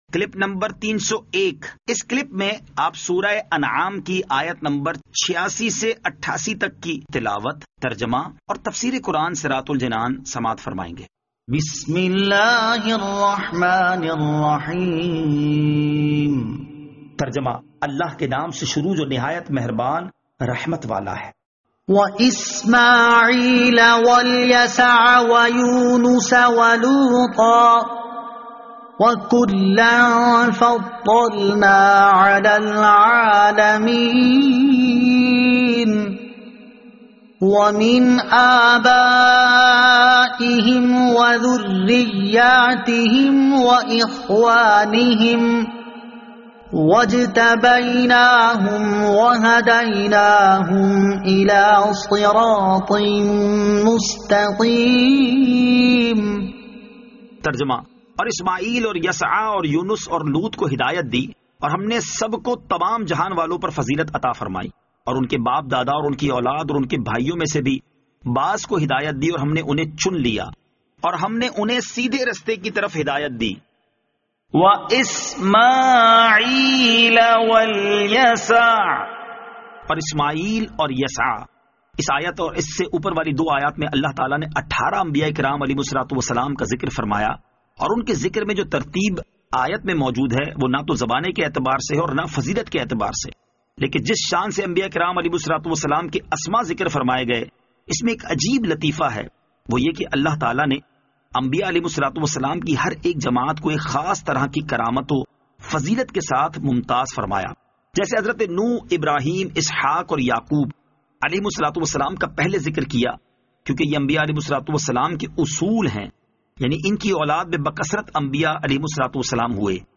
Surah Al-Anaam Ayat 86 To 88 Tilawat , Tarjama , Tafseer